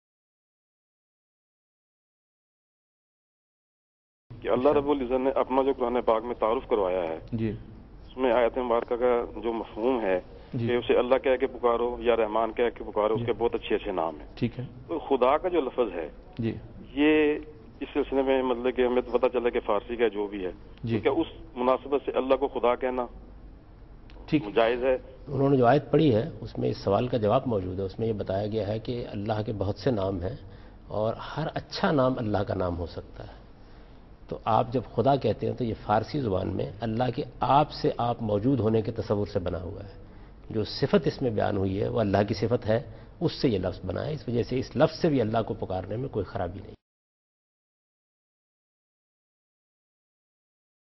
Javed Ahmad Ghamidi answering a question "Using the word "Khuda" for Allah?" in program Deen o Daanish on Dunya News.
جاوید احمد غامدی دنیا نیوز کے پروگرام دین و دانش میں ایک سوال "اللہ کی بجائے لفظ خدا کا استعمال" کا جواب دیتے ہیں۔